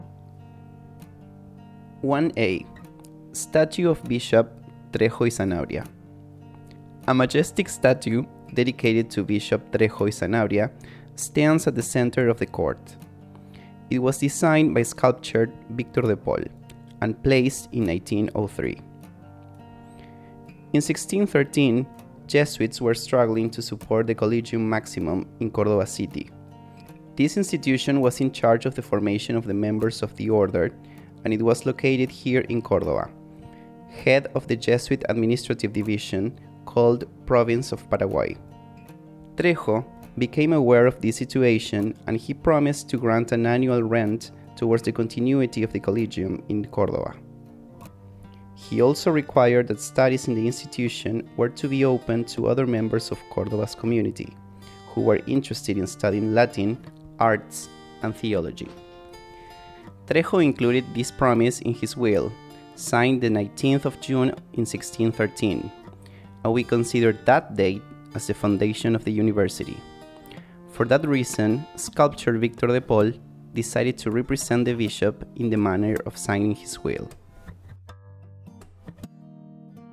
[Audioguide]